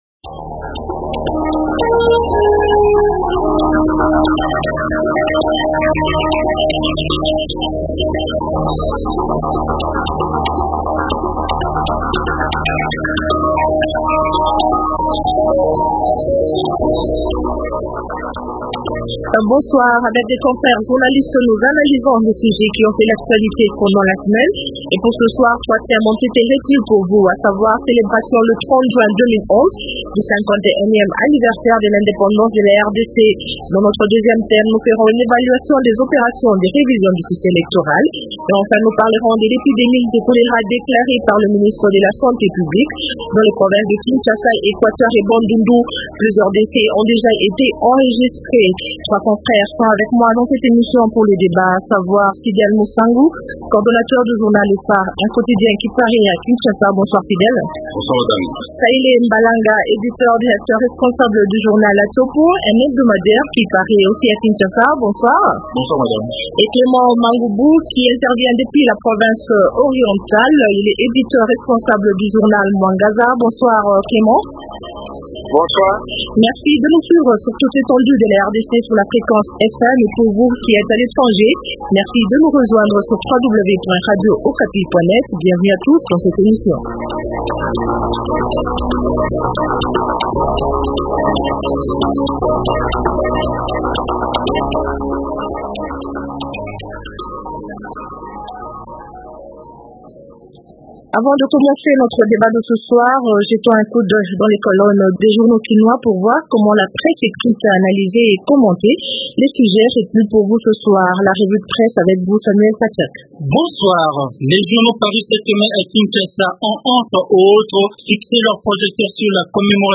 Trois thèmes sont au centre de cette tribune de presse.